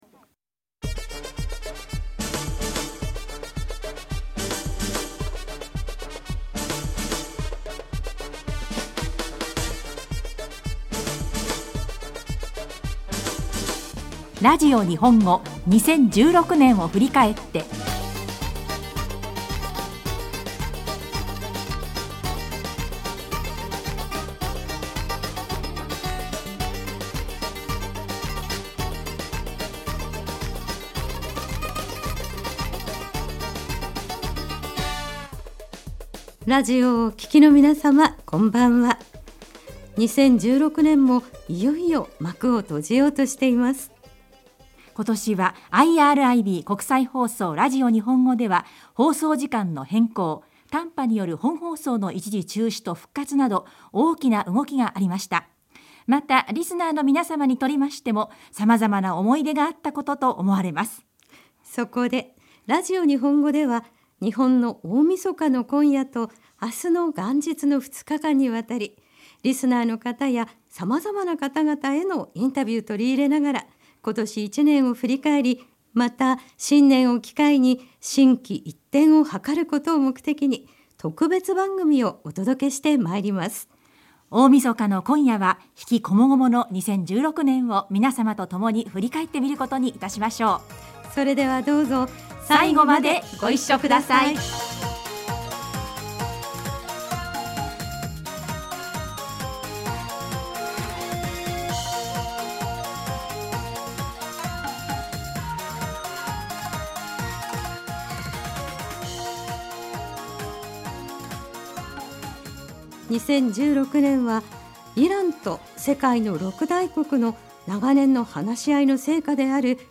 ラジオ日本語では日本の大晦日の今夜と明日の元日の２日間にわたり、リスナーの皆様へのインタビューを取り入れながら、今年１年を振り返り、また新年を機会に心機一転をはかることを目的に特別番組をお届けしてまいります。